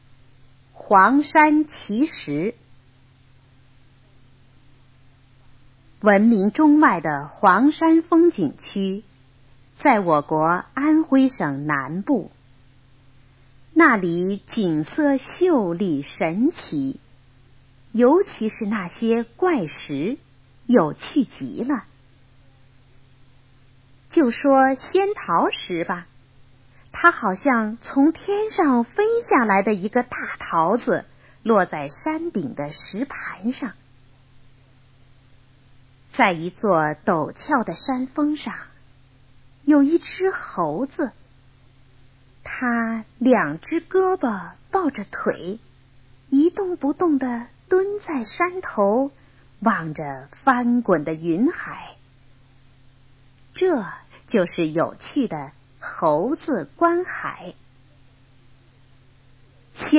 黄山奇石 课文朗读